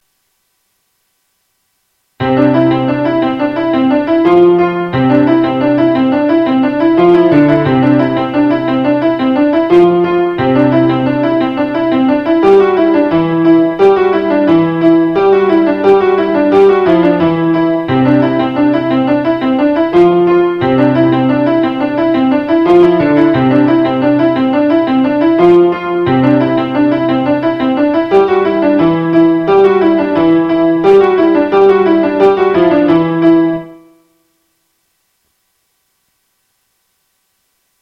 Acá pueden encontrar los temitas que compuse, todos super cortitos.
Los wavs fueron obtenidos de los midi sintetizándolos con un teclado Casio WK-200.
En general, el mp3 es bastante representativo de lo que quería componer (aunque tienen un poco de ruido de fondo no intencional, por no ser profesional en la grabación de lo sintetizado por el teclado :( ).
Solo de piano, melodía simple en mano derecha y acompañamiento de notas sueltas largas en la izquierda.